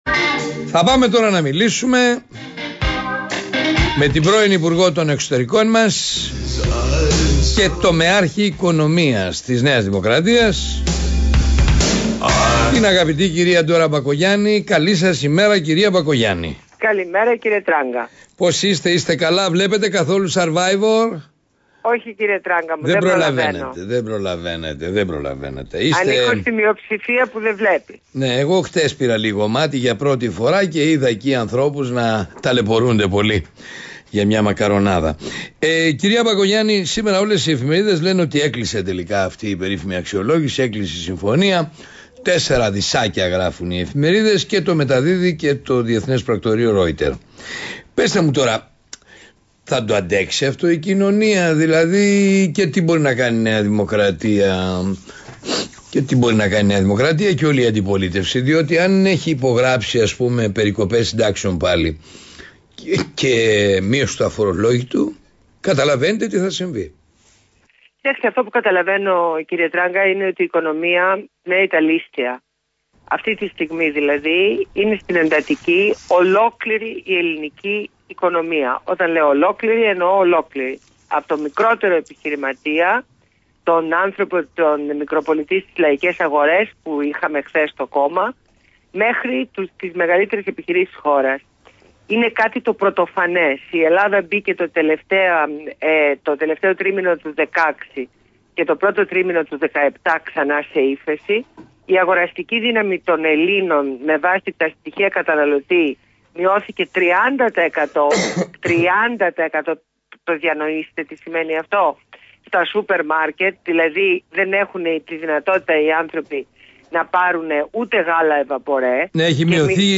Συνέντευξη στο ραδιόφωνο Παραπολιτικά 90,1fm